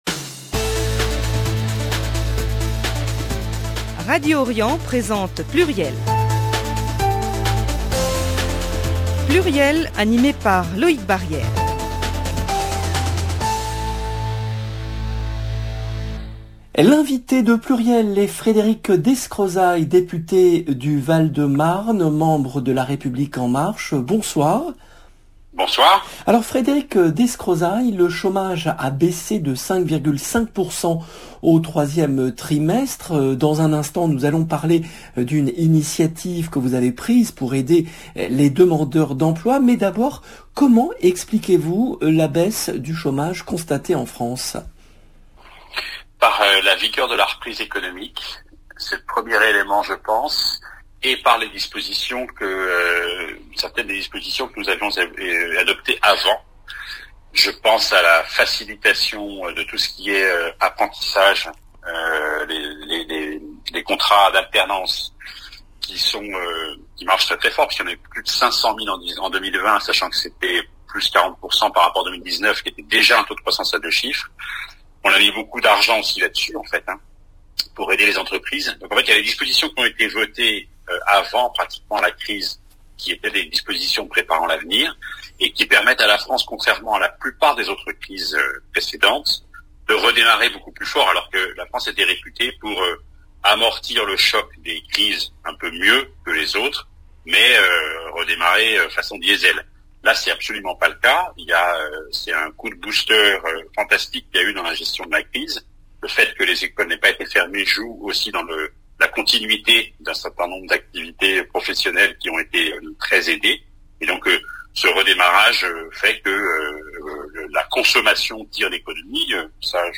PLURIEL, le rendez-vous politique du mercredi 3 novembre 2021
L’invité de PLURIEL est Frédéric Descrozaille , député LREM du Val-de-Marne